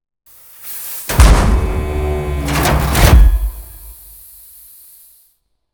Scrape1.wav